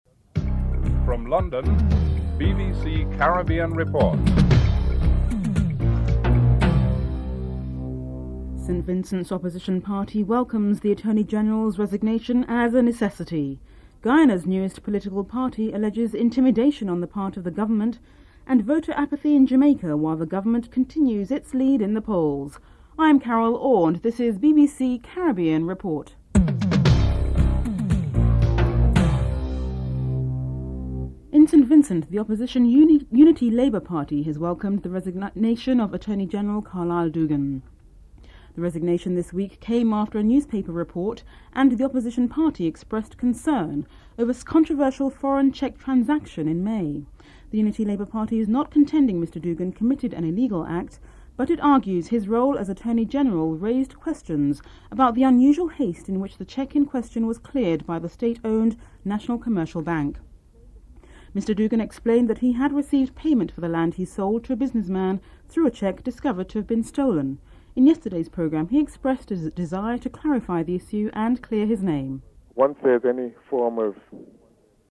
The British Broadcasting Corporation
1. Headlines (00:00-00:30)
2. St. Vincent's Opposition Party welcomes the Attorney General's resignation. Attorney General Carlyle Dougan and Ralph Gonsalves, United Labour Party, St. Vincent are interviewed (00:31-03:31)